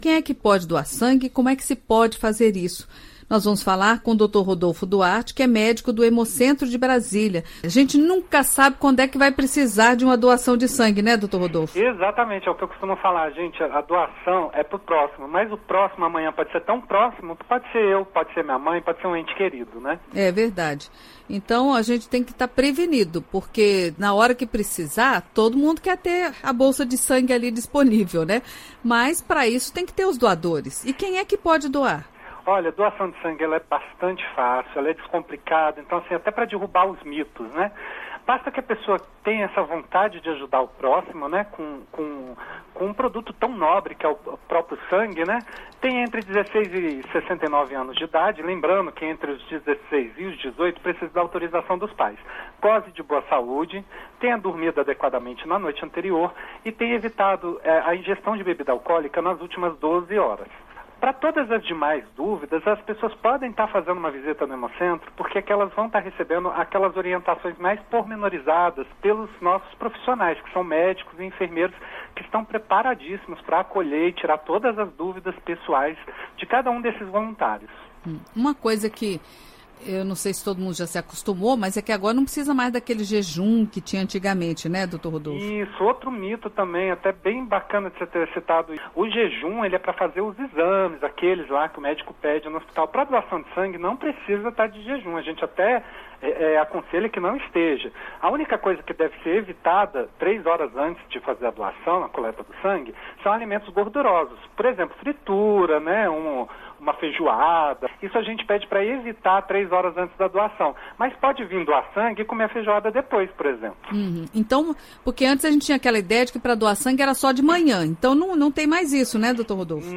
Entrevista: Saiba quais são os requisitos para doar sangue